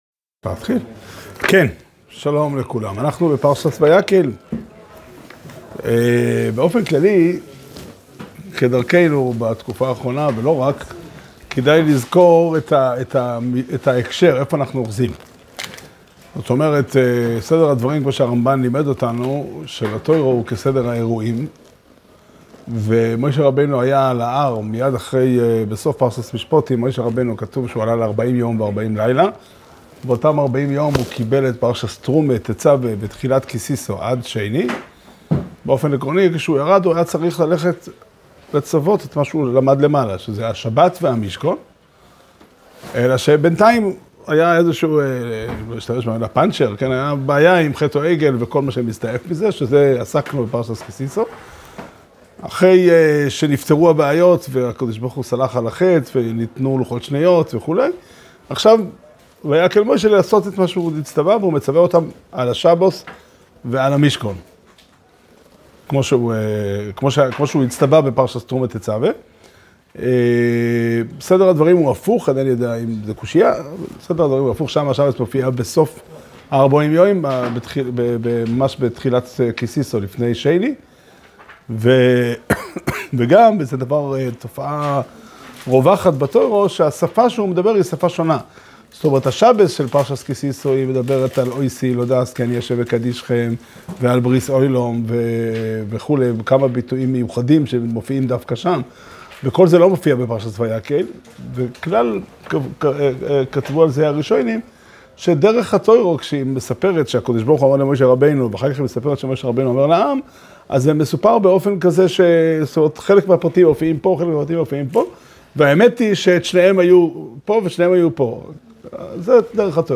שיעור שנמסר בבית המדרש פתחי עולם בתאריך כ"ג אדר א' תשפ"ד